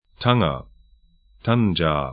Pronunciation
Tanger 'taŋɐ Ţanjah ar Stadt / town 35°48'N, 05°45'W